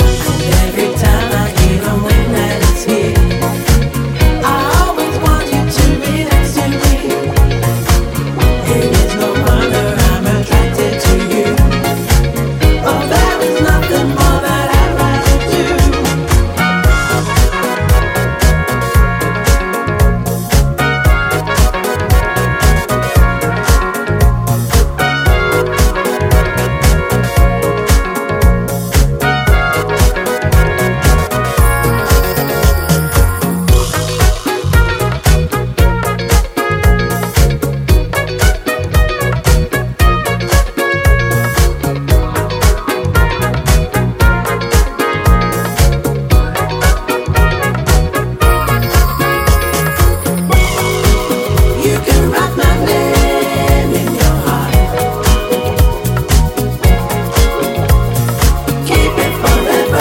here given a lush post-disco treatment
vocals
trumpet